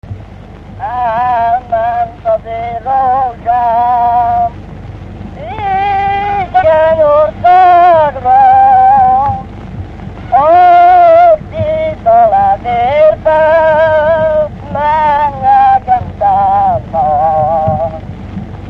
Dallampélda: Elment az én rózsám
Elment az én rózsám Dunántúl - Tolna vm. - Őcsény Előadó
ének Gyűjtő